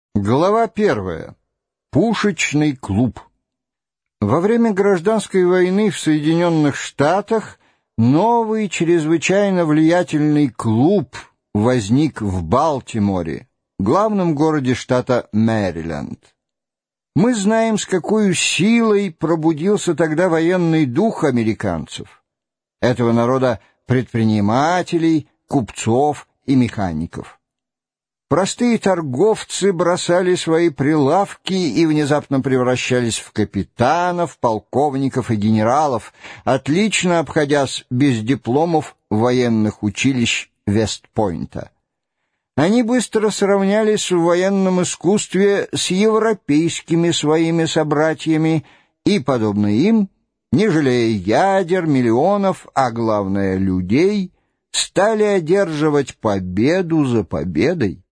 Аудиокнига С Земли на Луну | Библиотека аудиокниг